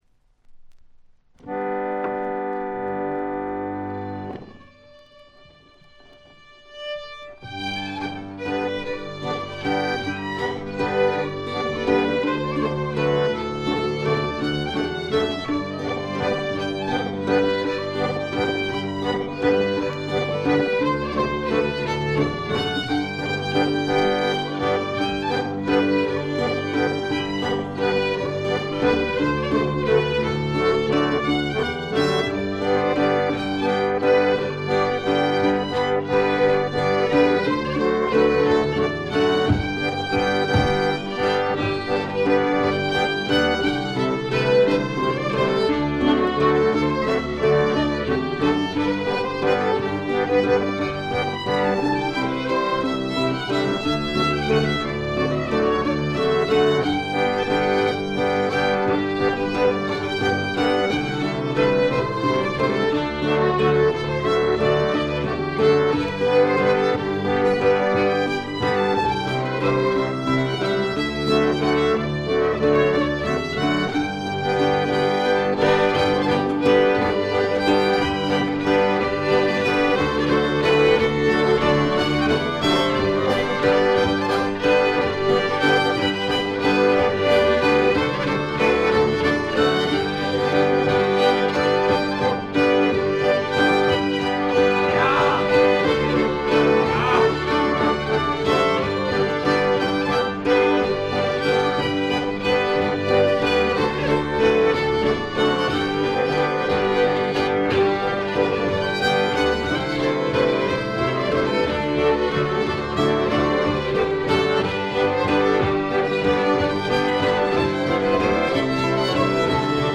スウェーデンのトラッド・グループ
試聴曲は現品からの取り込み音源です。